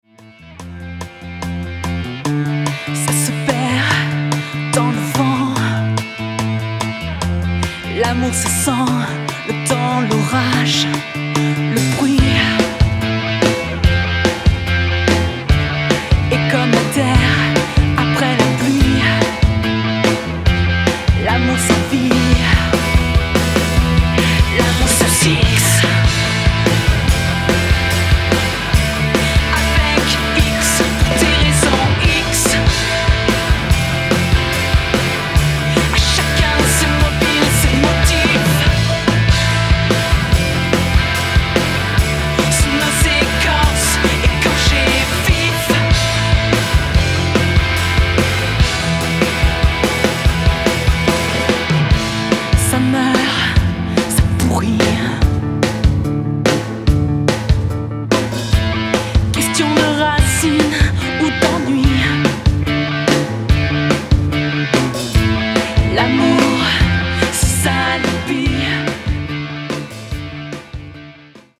pop rock
Duo pop rock avec une pointe d'électro